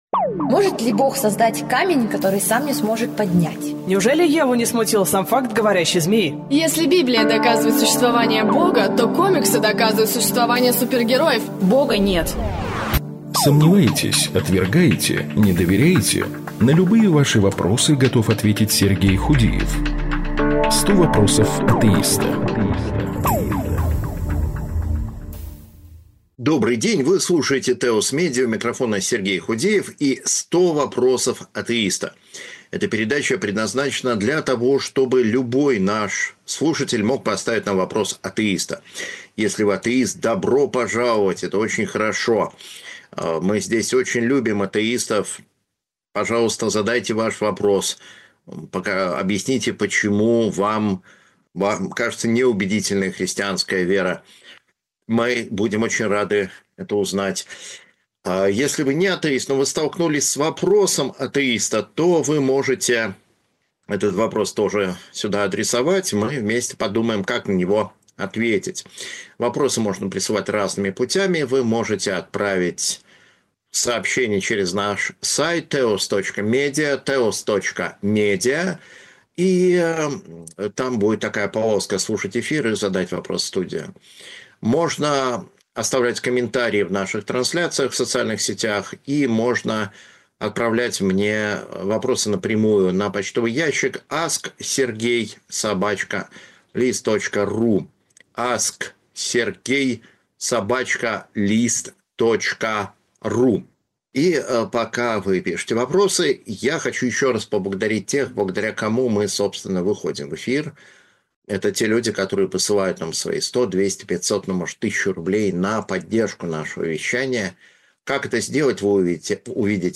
В прямом эфире